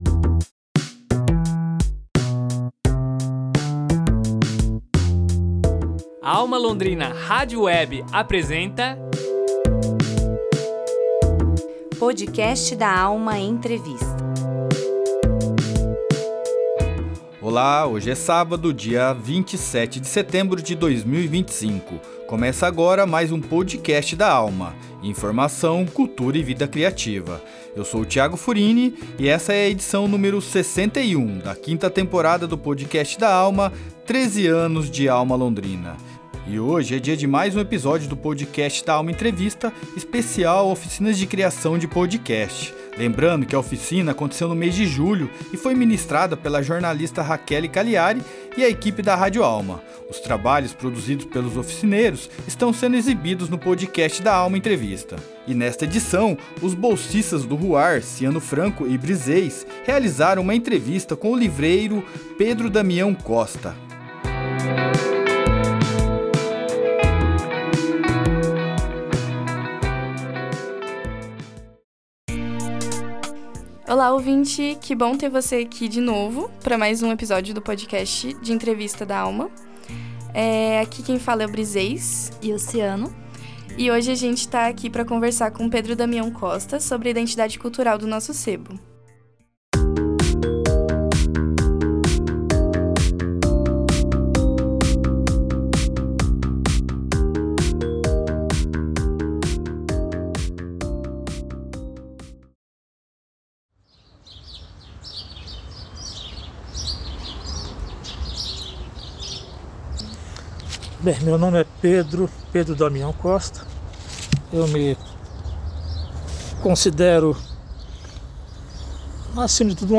entrevista especial